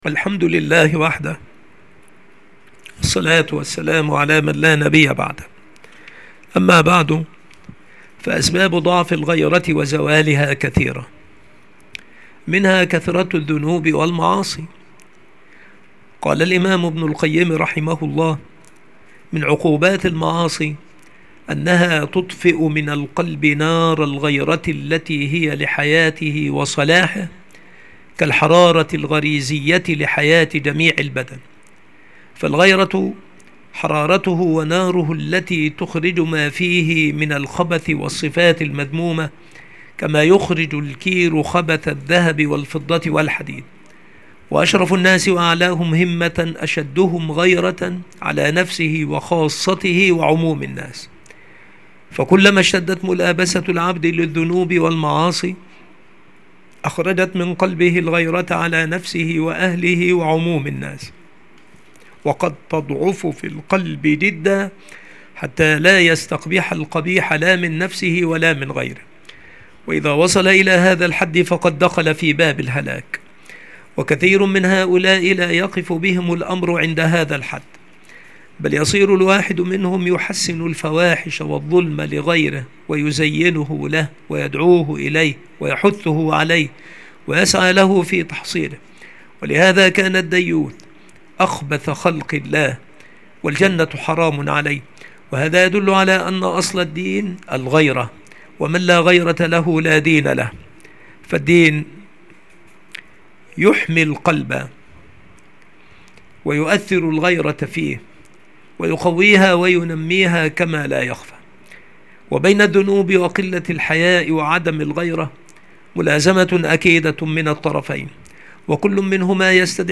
المحاضرة
مكان إلقاء هذه المحاضرة المكتبة - سبك الأحد - أشمون - محافظة المنوفية - مصر